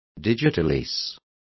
Complete with pronunciation of the translation of digitalises.